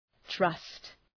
Προφορά
{trʌst}